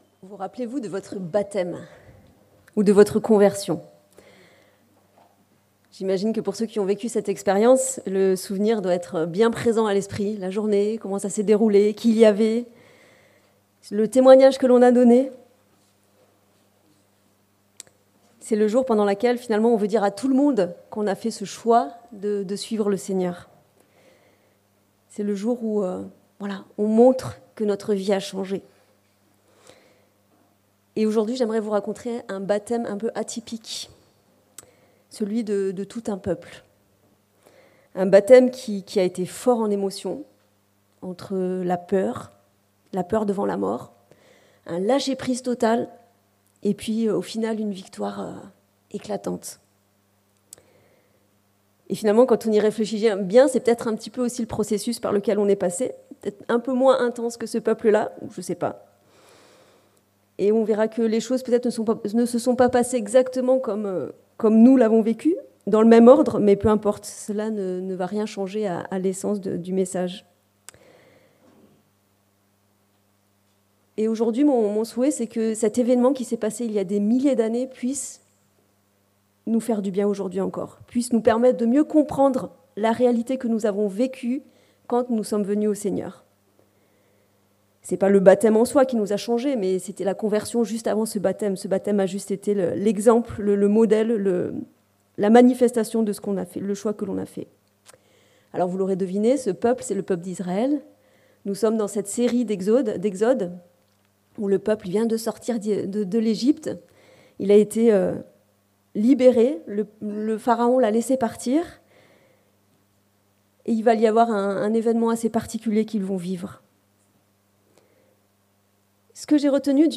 Le baptême des Israélites - Prédication de l'Eglise Protestante Evangélique de Crest sur le livre de l'Exode